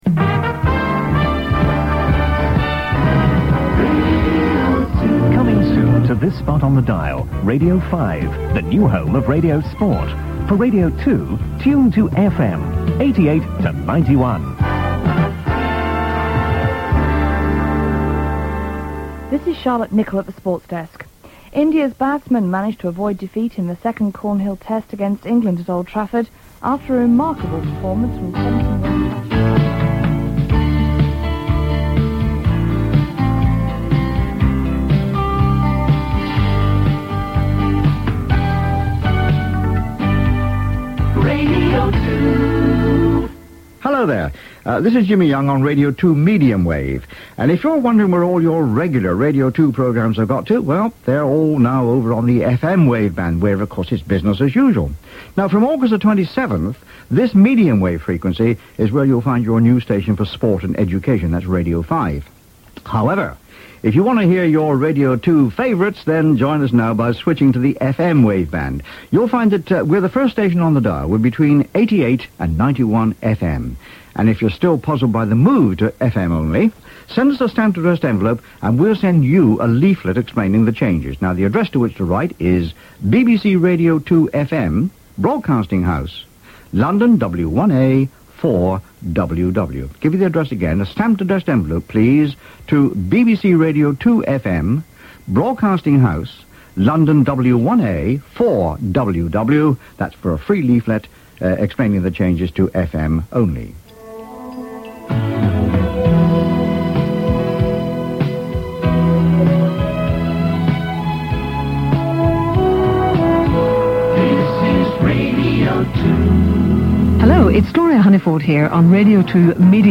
Clips from the BBC Radio 2 information service that ran on medium wave between 15 August and 26 August 1990 in the run-up to the launch of BBC Radio 5.